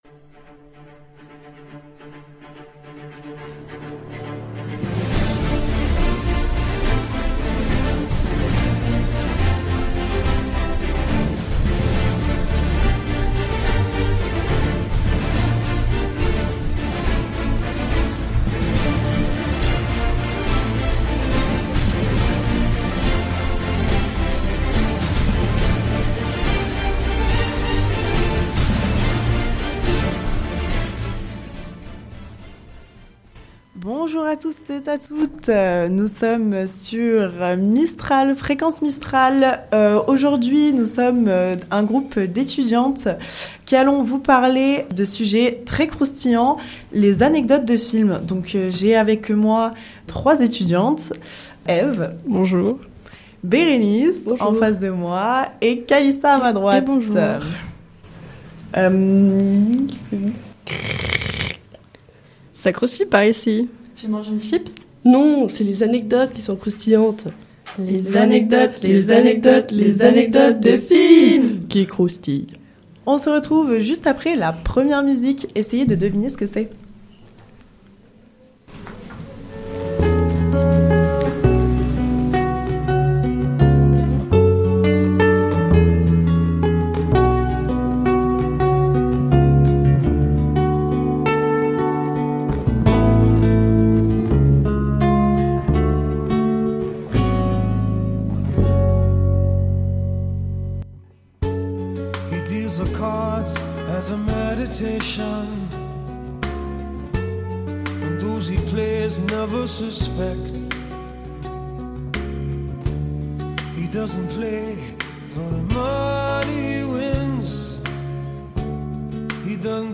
Une émission réalisée entièrement par des étudiantes 2ème année Agronomie de l'IUT d'Aix-Marseille Site de Digne-les-Bains saison 2023-2024.